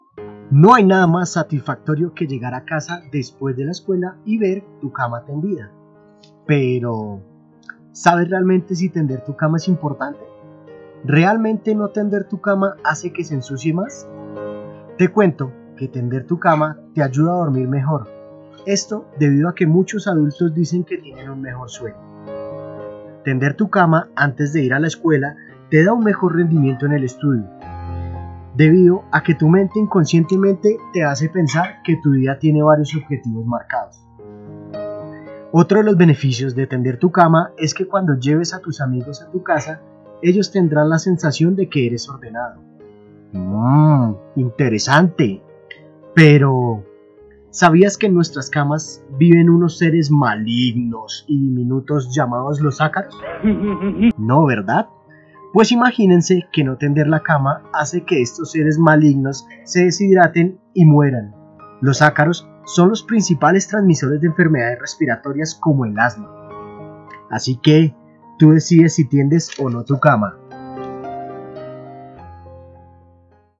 Producción radial, Ácaros